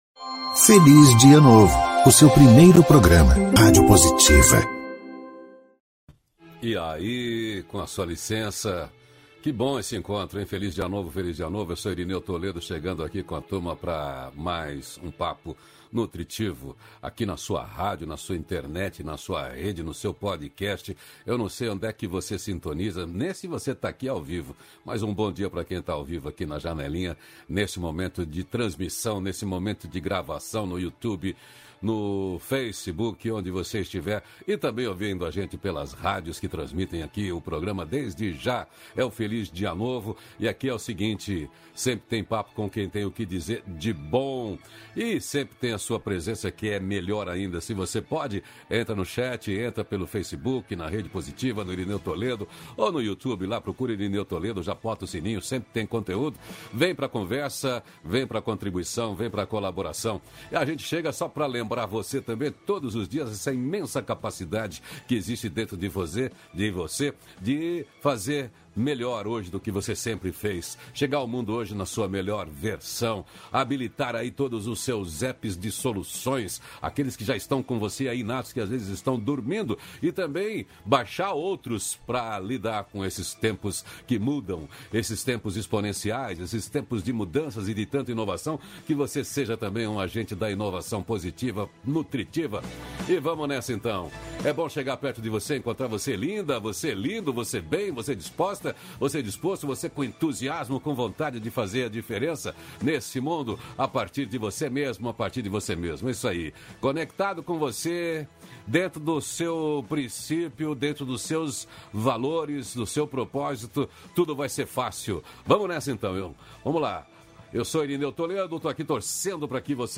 -640FelizDiaNovo-Entrevista.mp3